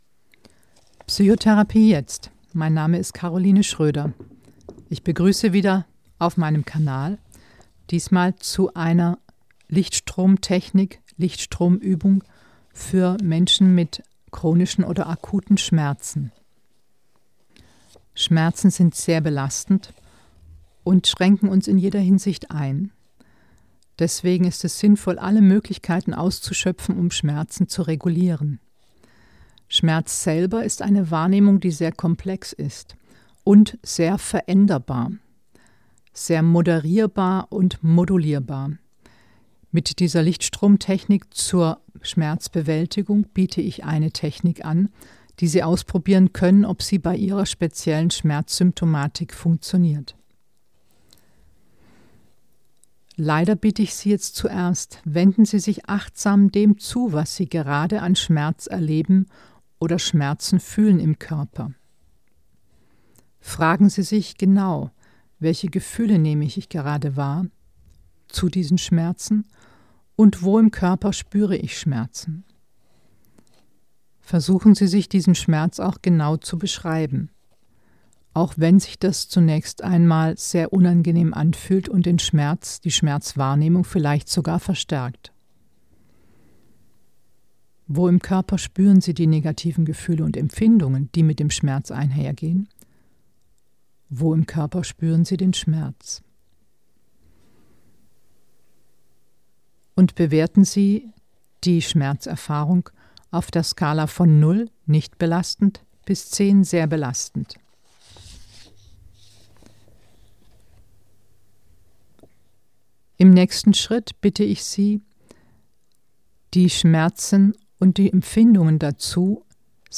Licht-Strom-Übung zur Schmerzbewältigung mit auditiver BLS Bilateraler Stimulation